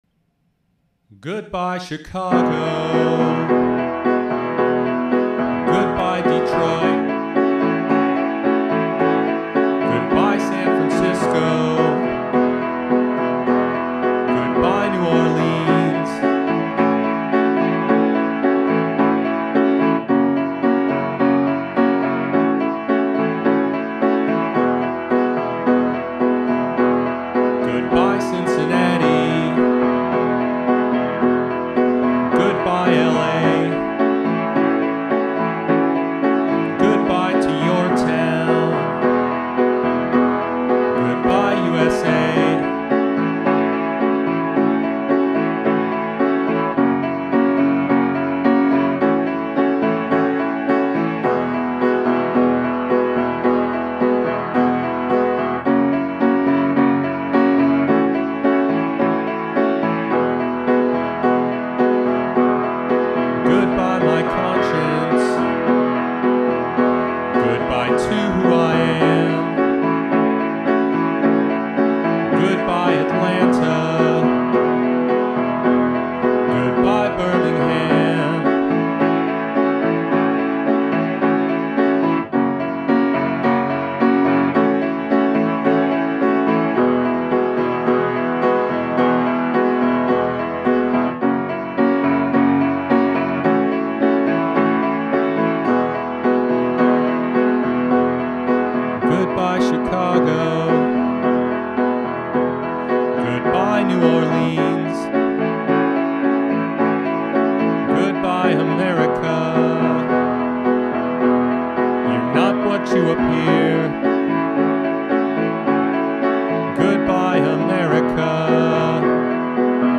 rock opera
piano and voice